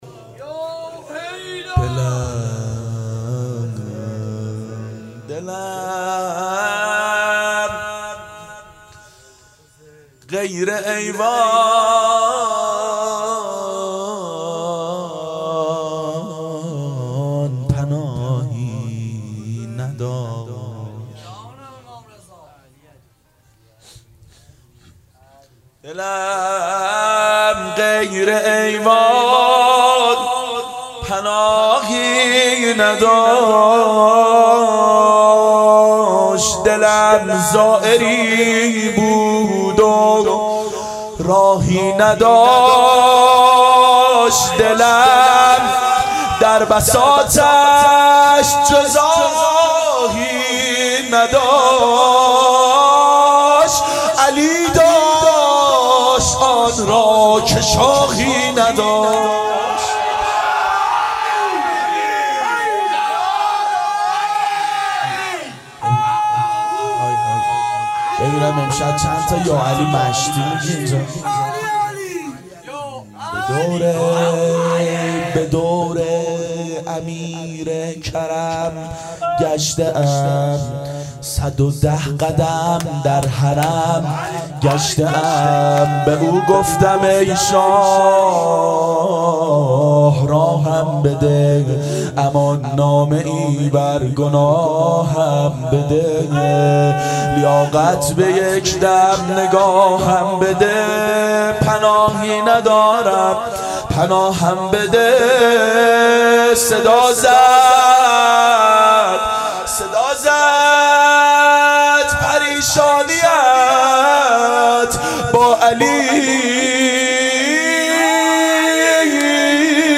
• دهه اول صفر سال 1390 هیئت شیفتگان حضرت رقیه س شب سوم (شام غریبان)